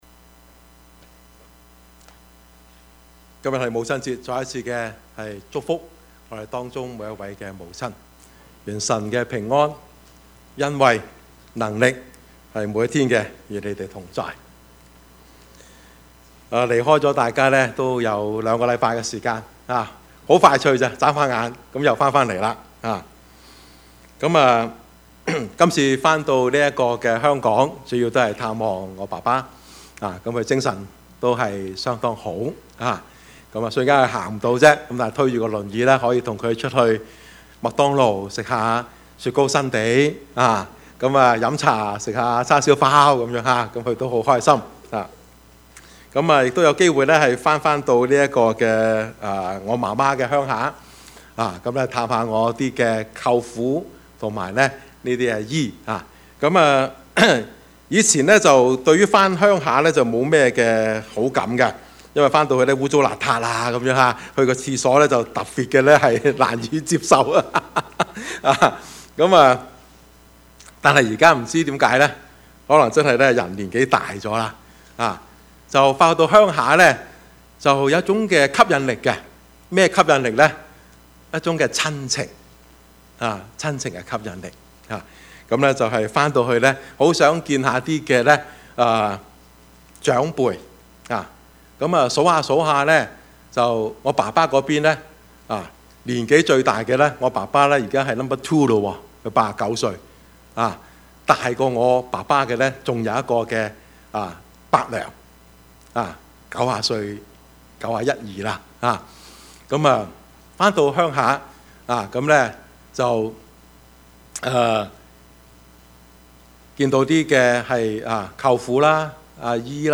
Service Type: 主日崇拜
Topics: 主日證道 « 得知叛逆需回轉 團契與關懷 »